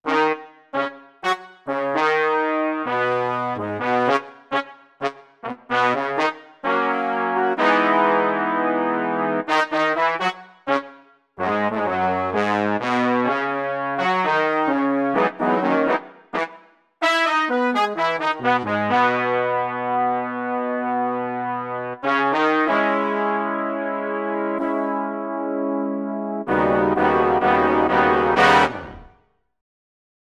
14 brass 5 D.wav